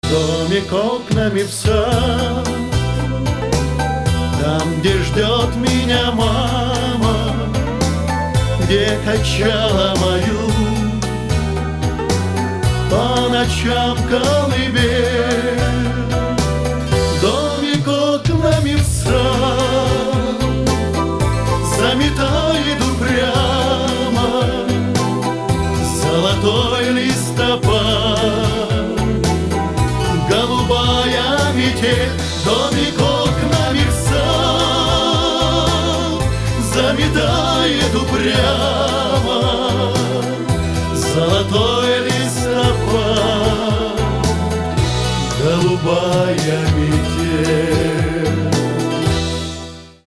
• Качество: 320, Stereo
мужской вокал
душевные
спокойные
дуэт
русский шансон
ностальгия